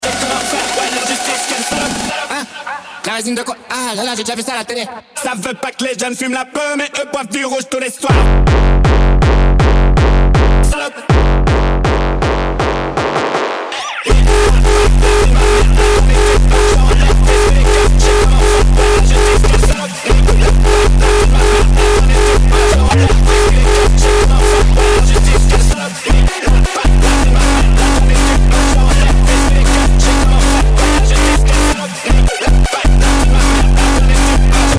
remixé en Uptempo
gros kicks, rawtempo, zaagkicks, hard techno, tekno